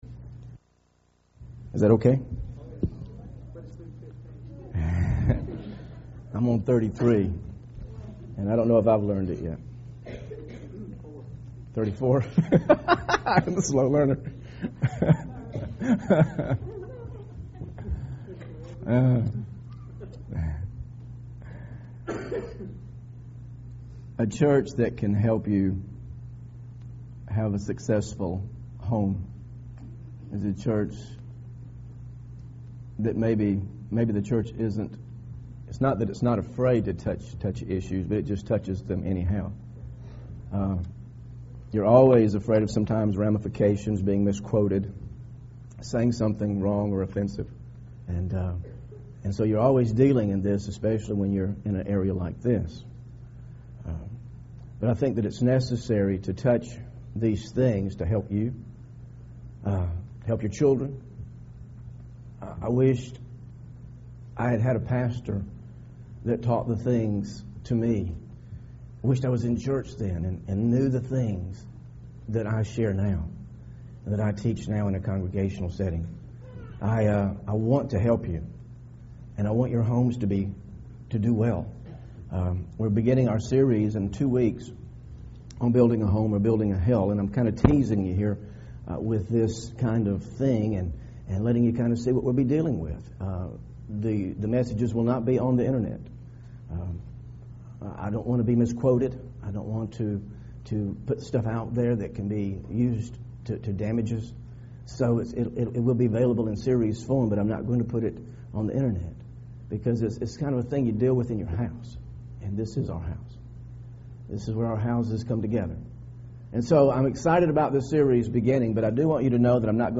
VALENTINE SERMON